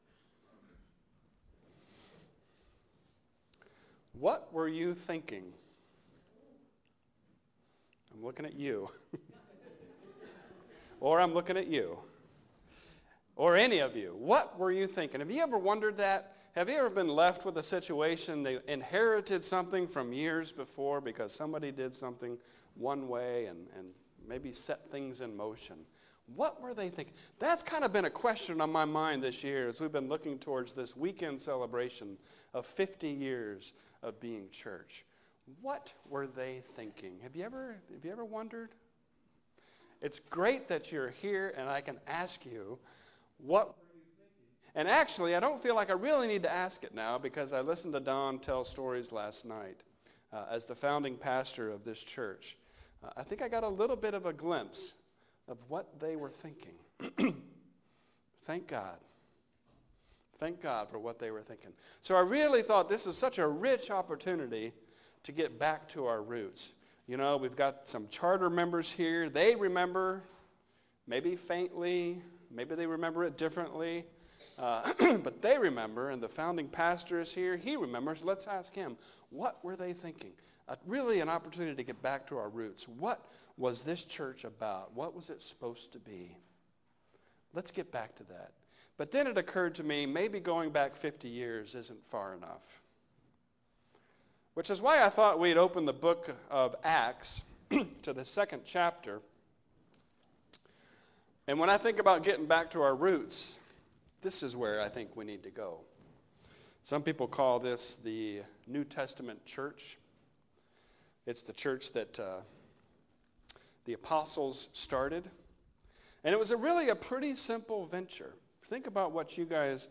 Below is a collection of sermons preached at Covenant Christian Church